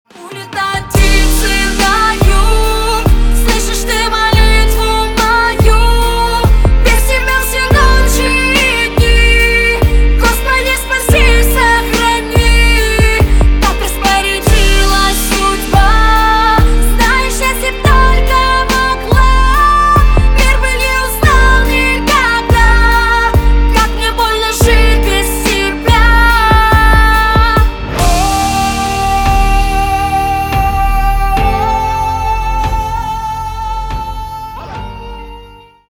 бесплатный рингтон в виде самого яркого фрагмента из песни
Поп Музыка
громкие